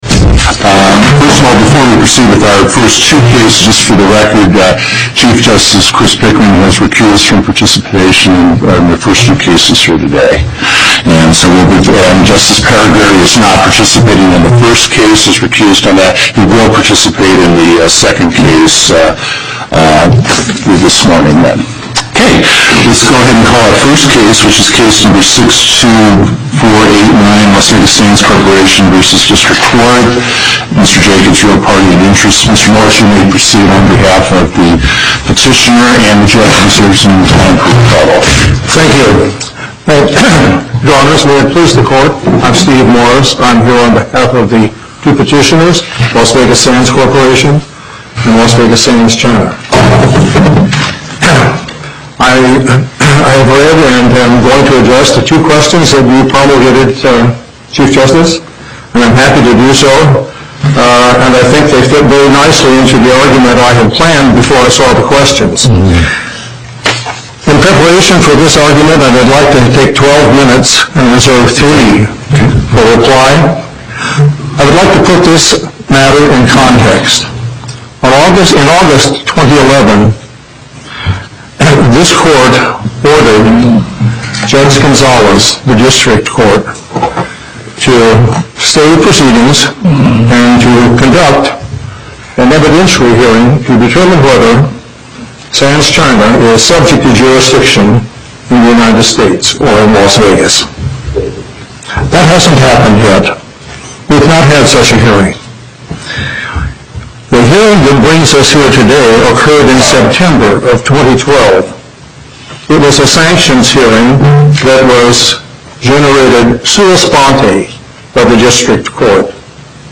Location: Las Vegas Before the En Banc Court, Justice Gibbons Presiding
as counsel for the Petitioners